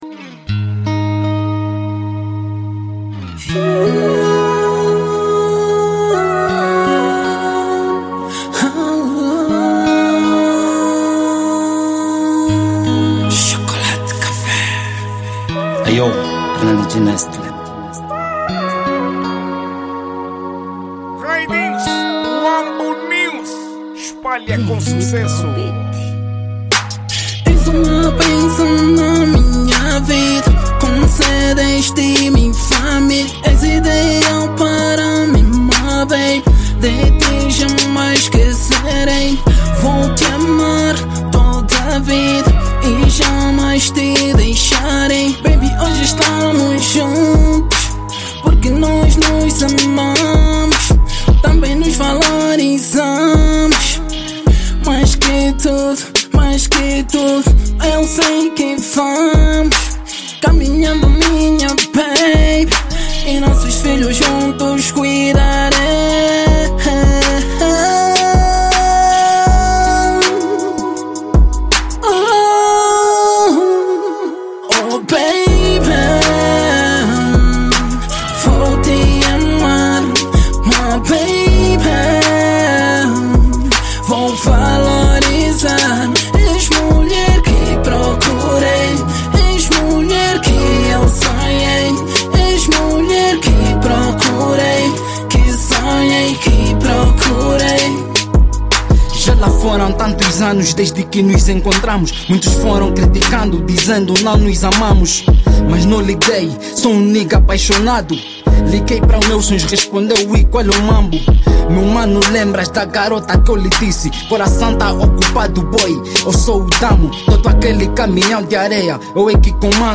| Rap